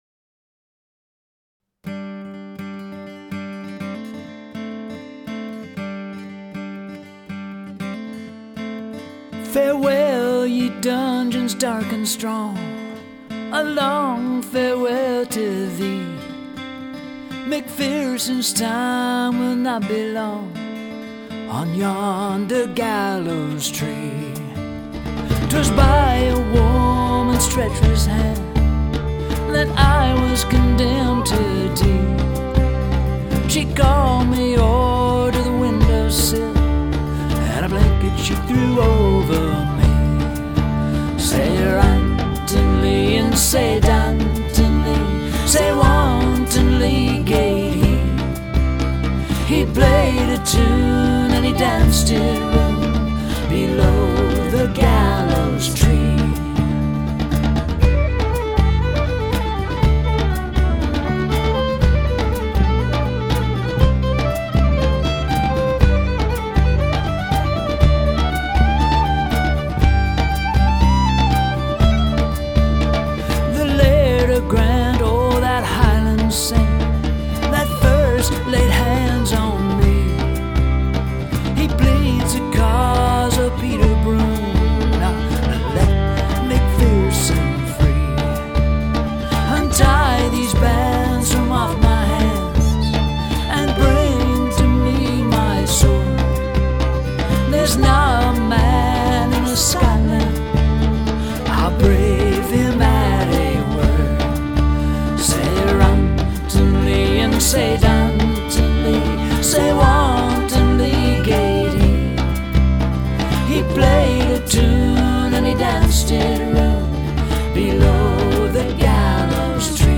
Celtic Acoustic tune
Hey y'all, This is my wife's Celtic acoustic band doing a traditional song called "MacPherson's Rant."